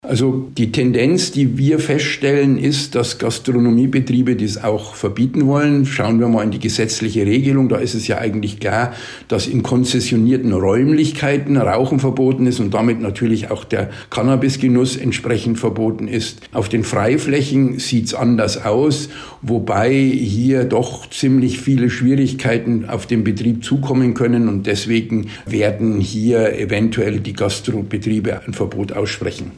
Interview: Cannabis-Teillegalisierung - das sagt die DEHOGA - PRIMATON